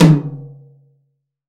H-TOM10-1 -L.wav